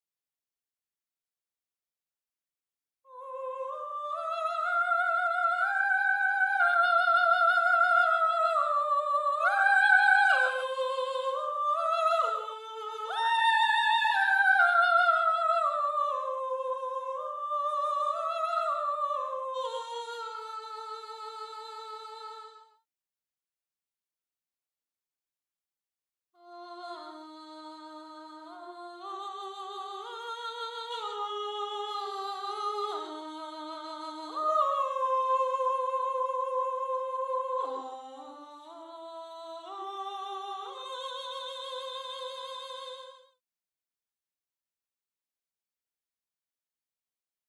Voice 2 (Soprano/Soprano)
gallon-v8sp5-20-Soprano_1.mp3